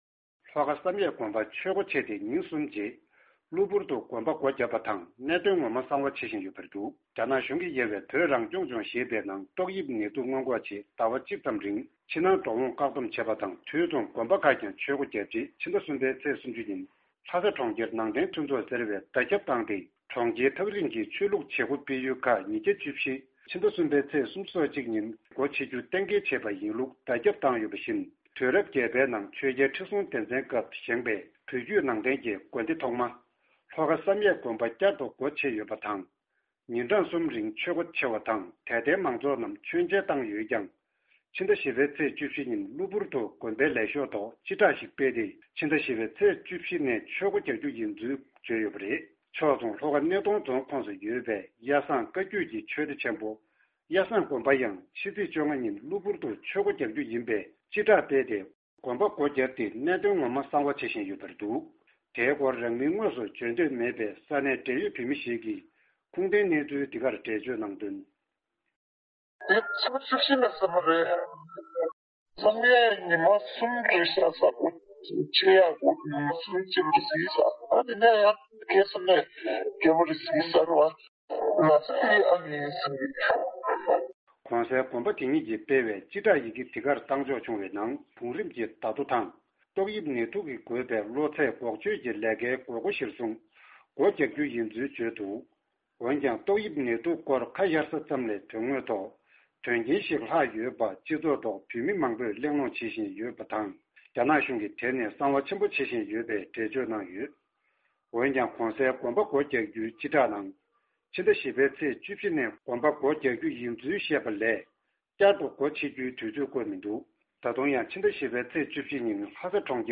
རྒྱ་གར་ལྷོ་ཕྱོགས་ནས་ཨ་རིའི་རླུང་འཕྲིན ཁང་གི་གསར་འགོད་པ